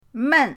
men4.mp3